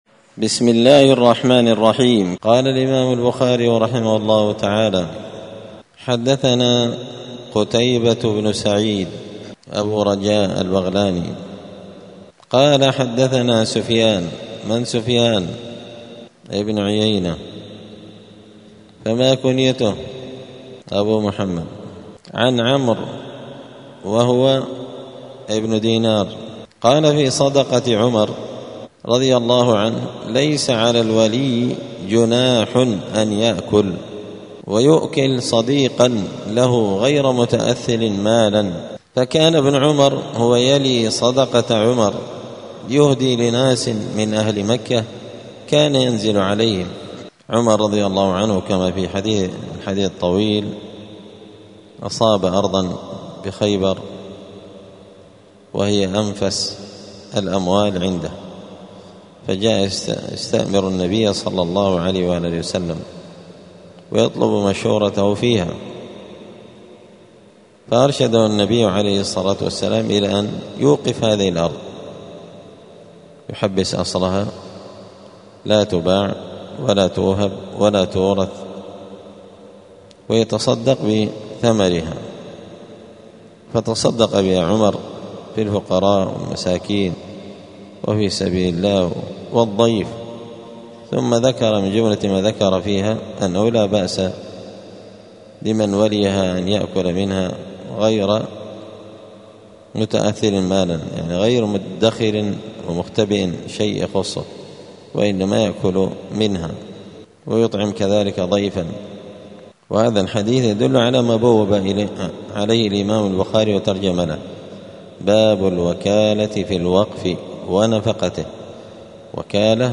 دار الحديث السلفية بمسجد الفرقان قشن المهرة اليمن
الثلاثاء 8 ربيع الثاني 1447 هــــ | الدروس، دروس الحديث وعلومه، شرح صحيح البخاري، كتاب الوكالة من صحيح البخاري | شارك بتعليقك | 8 المشاهدات